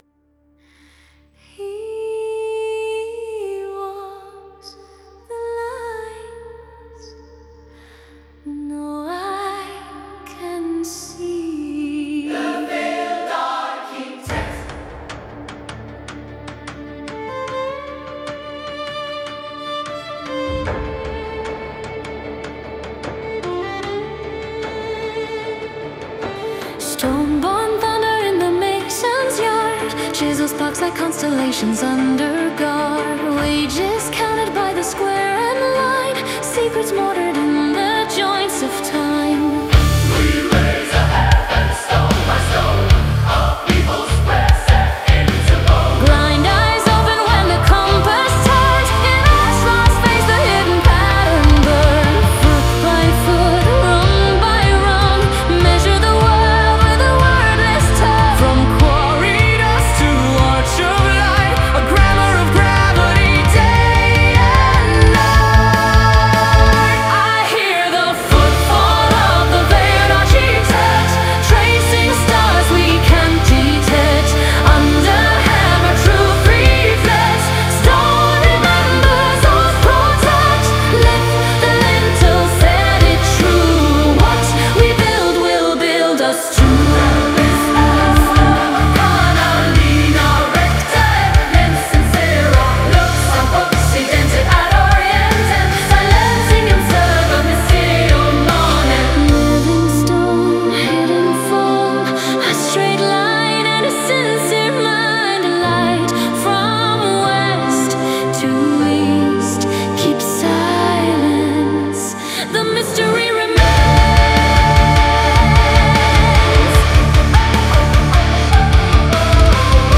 power-prog epic